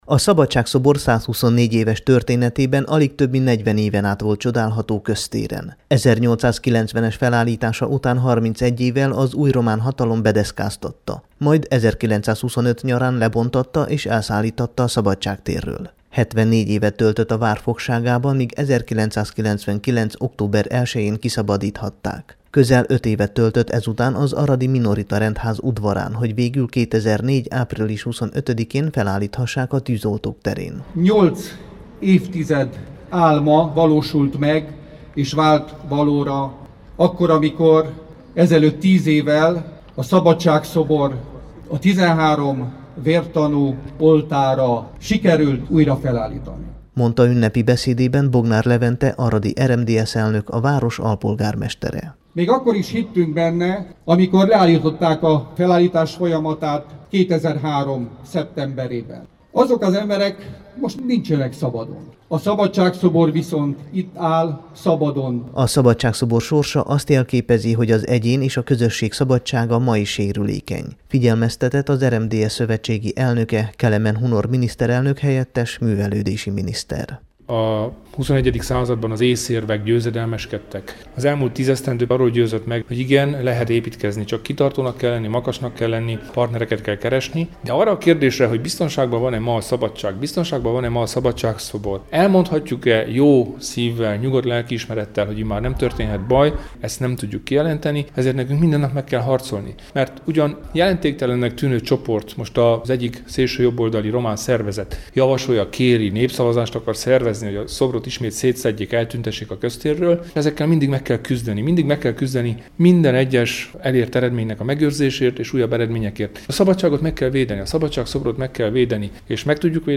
Tiz_eve_all_a_Szabadsag-szobor_beszamolo.mp3